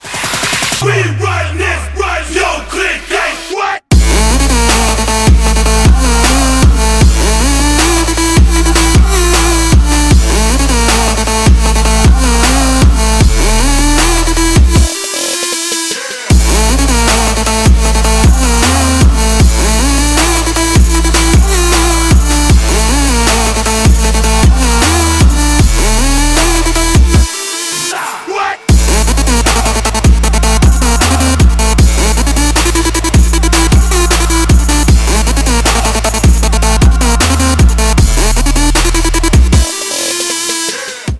• Качество: 128, Stereo
громкие
восточные мотивы
Electronic
мощные басы
Trap
hard trap